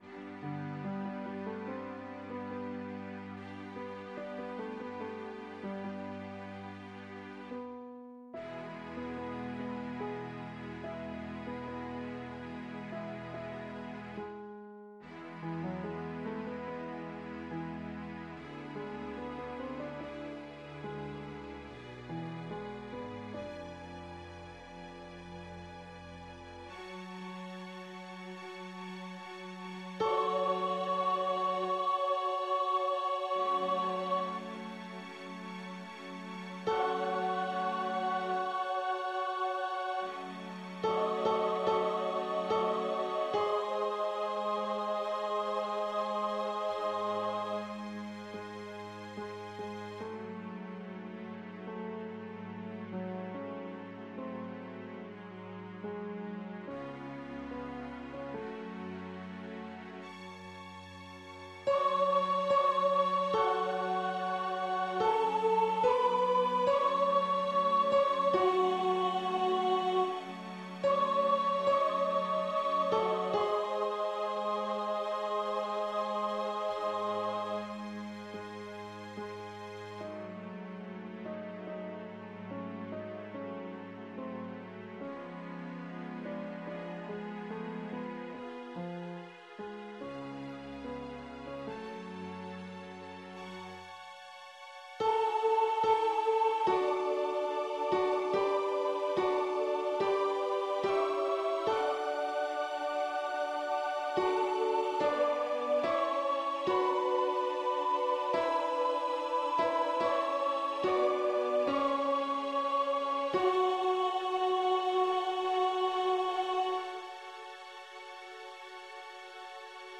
Les fichiers de travail mettent en relief au piano chacune des voix dans le contexte général ; ce sont des fichiers .mp3 qui peuvent être lus par un ordinateur, un lecteur mp3, ou directement gravés sur un CD audio.
Lorsque le choix en a été possible, le tempo des fichiers de travail est bien entendu un tempo de travail, et ne reflète pas nécessairement celui de notre interprétation finale...